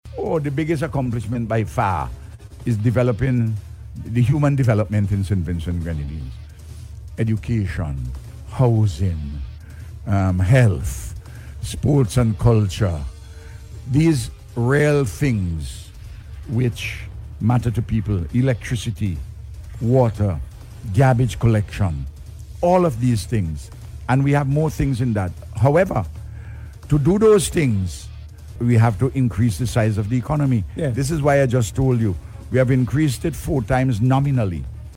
Speaking on Radio this week, Prime Minister Dr. Ralph Gonsalves highlighted advancements in infrastructure, healthcare, education and other sectors.